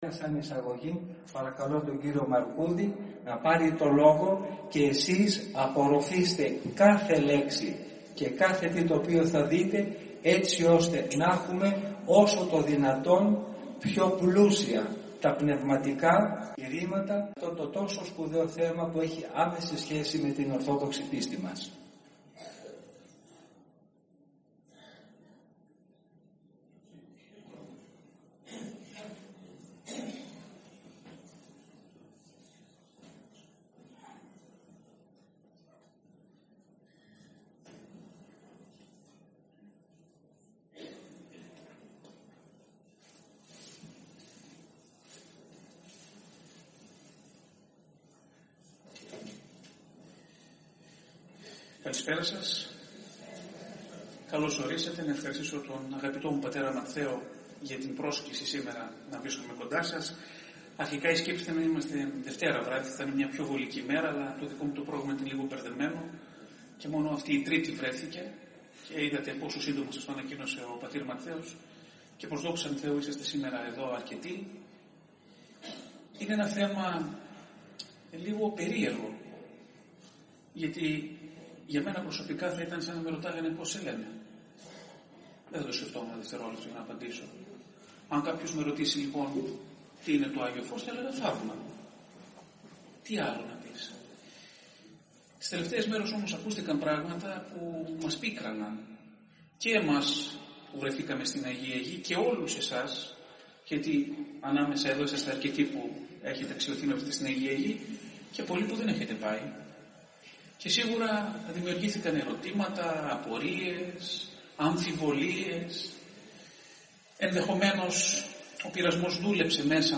Επειδή, υπάρχει πλήθος μαρτυριών από τους πρώτους χριστιανικούς αιώνες μέχρι σήμερα από αυτόπτες μάρτυρες, αρκετές εξ αυτών και καταγεγραμμένες ώστε να μην είναι δυνατό να γίνει αποδεκτός ο ισχυρισμός ότι το Άγιο φως προέρχεται από συνήθεις ανθρώπινες ενέργειες, πολύ περισσότερο, ότι η Αγία μας Εκκλησία διαπράττει απάτη, ο Ιερός Ναός Αγίου Δημητρίου Ομωνύμου Δήμου πραγματοποίησε την Τετάρτη, 2 Απριλίου 2019, και ώρα 6:30 το απόγευμα, εκδήλωση αφιερωμένη στο Άγιο Φως, με την παρουσία πλήθους πιστών.